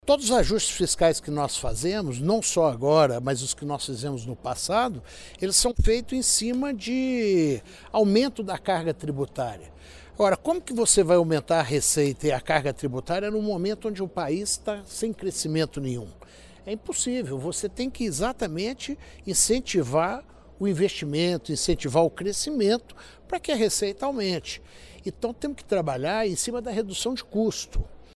Em seu discurso, o presidente da CNI cobrou atenção à qualidade do ajuste fiscal e destacou as medidas defendidas pela indústria para melhorar o ambiente de negócios, sem comprometer o processo de ajuste fiscal.